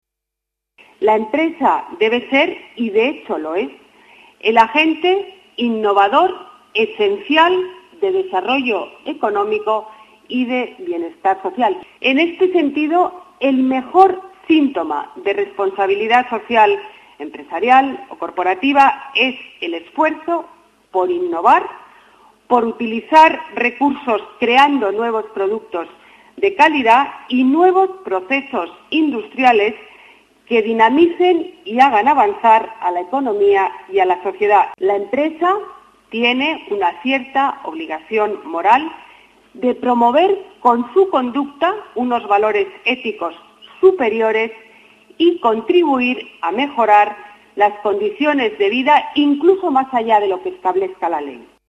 Botella inaugura el Congreso y Salón de Responsabilidad Social Corporativa en el Palacio Municipal de Congresos
Nueva ventana:Declaraciones de Ana Botella, concejala de Empleo y servicios a la Ciudadanía